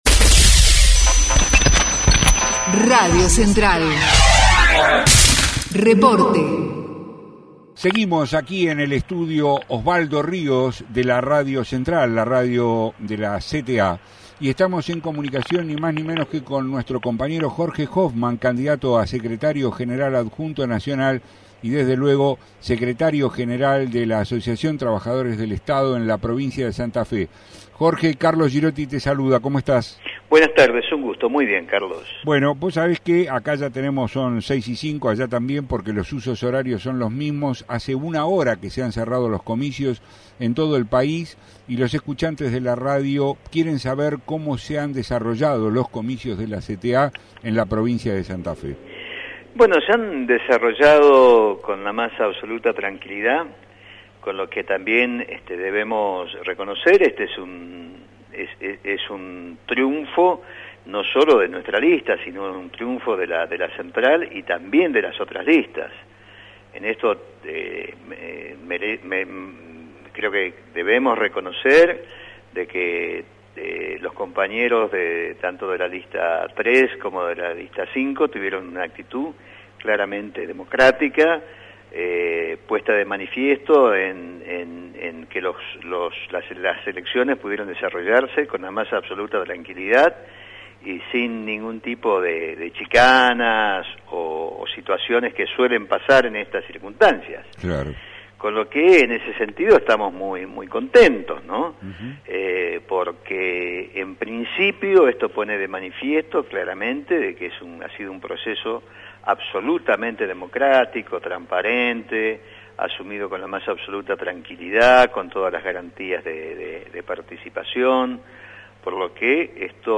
entrevista) RADIO CENTRAL